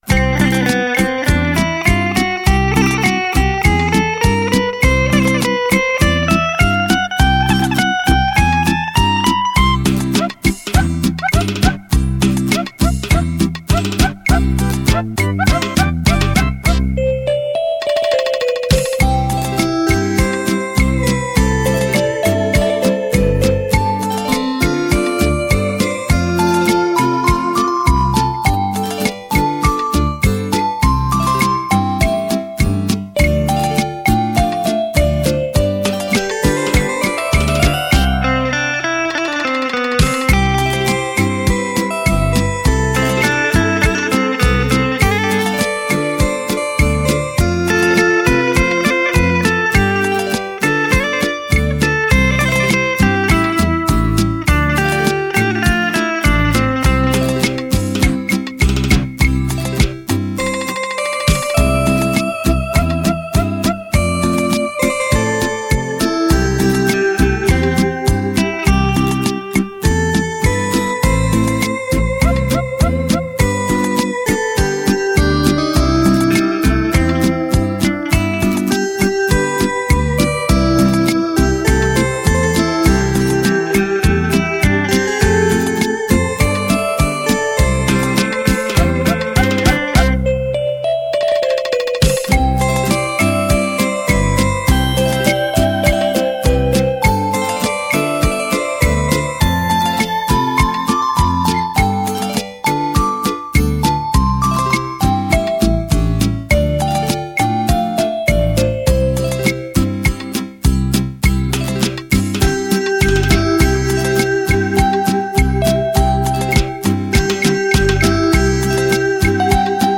纯净晶莹 柔情似水的乐曲